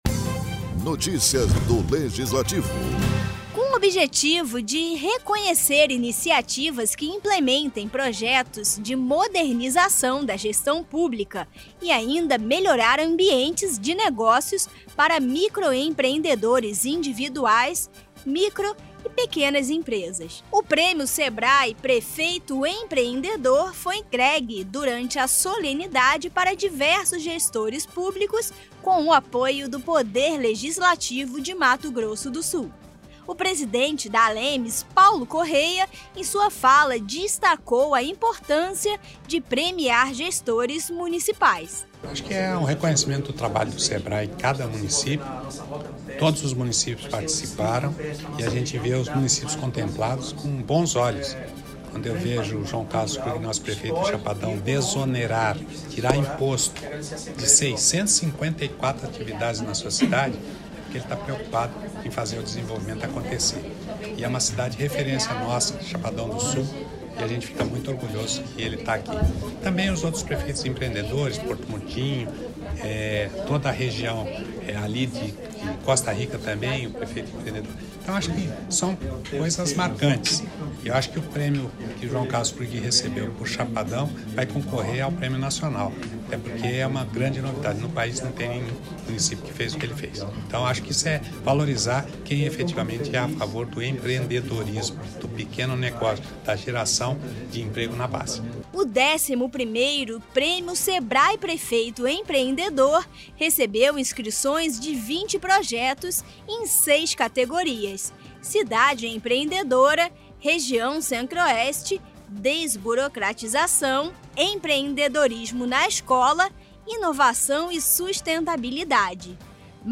Durante evento na ALEMS, municípios empreendedores de MS são premiados